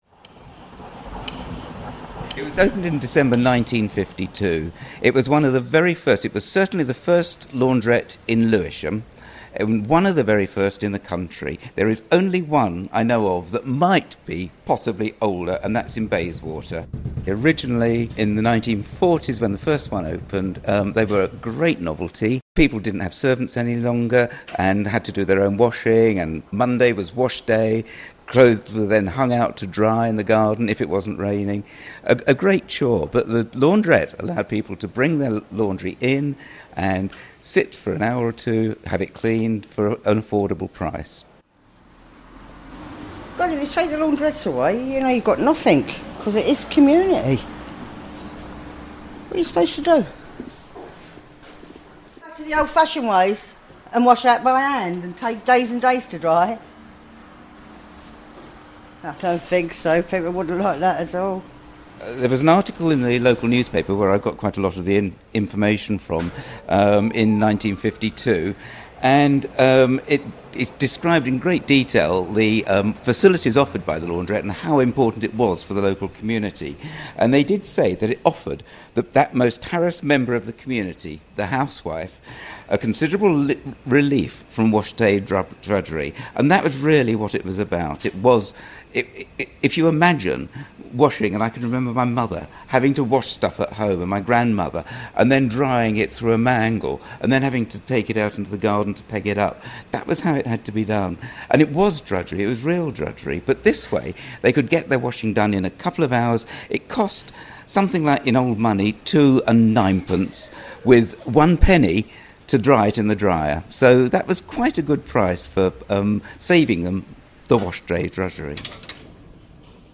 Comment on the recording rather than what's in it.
came to meet me at the launderette. He explained how launderettes changed the way society washed their clothes.